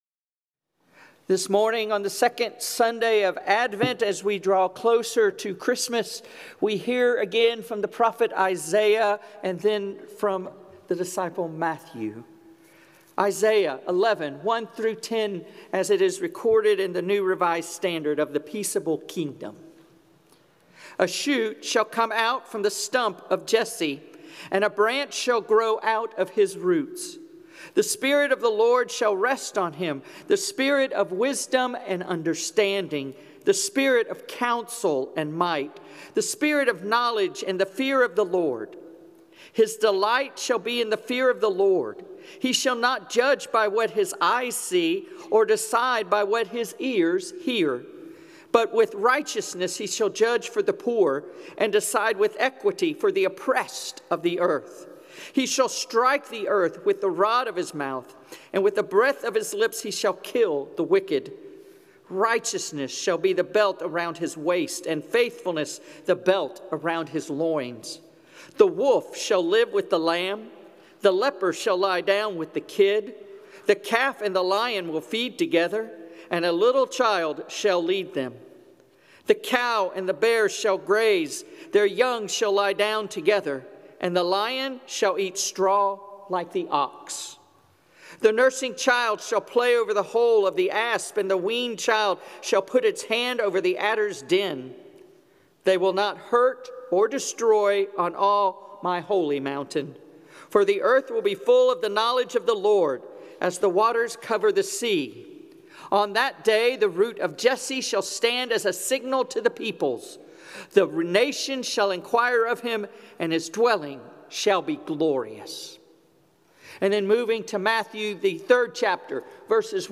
Christmas Homily